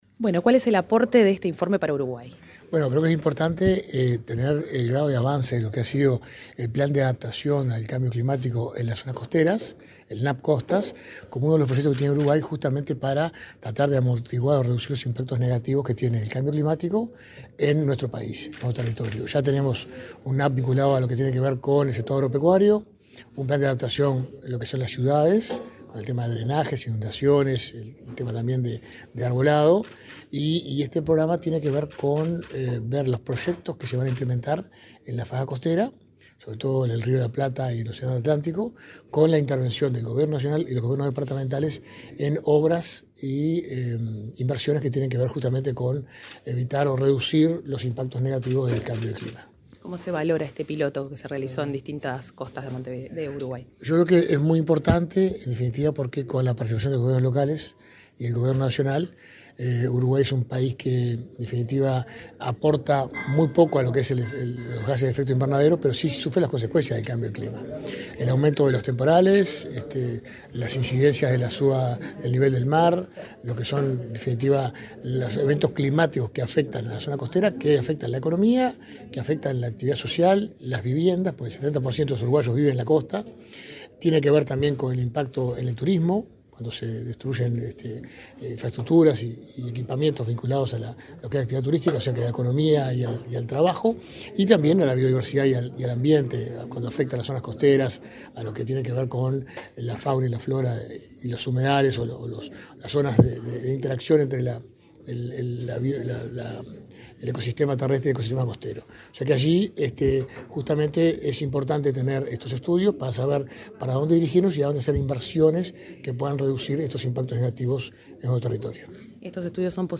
Entrevista al subsecretario de Ambiente, Gerardo Amarilla
Entrevista al subsecretario de Ambiente, Gerardo Amarilla 10/07/2024 Compartir Facebook X Copiar enlace WhatsApp LinkedIn El subsecretario de Ambiente, Gerardo Amarilla, dialogó con Comunicación Presidencial en Torre Ejecutiva, antes de la presentación de resultados sobre avances en la implementación de medidas de adaptación al cambio climático en la zona costera uruguaya.